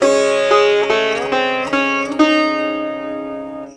SITAR LINE20.wav